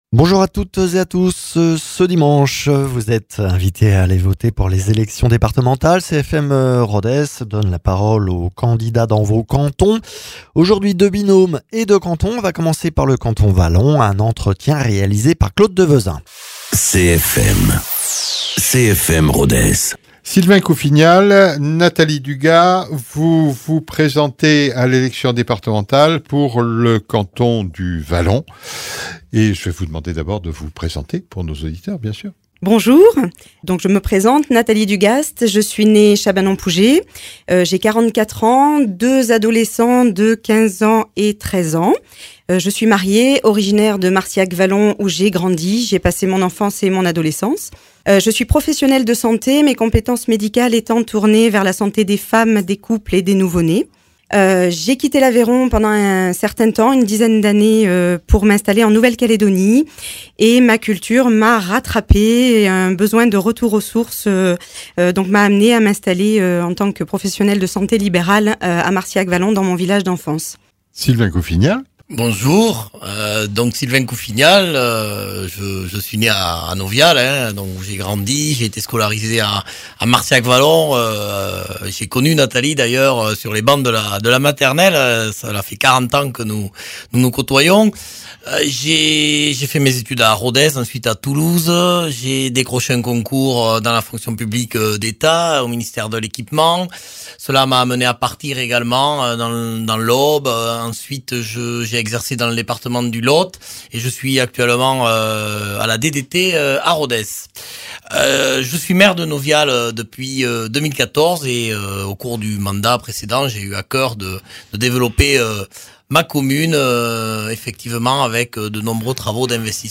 Dans la cadre des élections départementales des 20 et 27 juin, CFM Rodez invite les candidats des 6 cantons de son bassin de diffusion.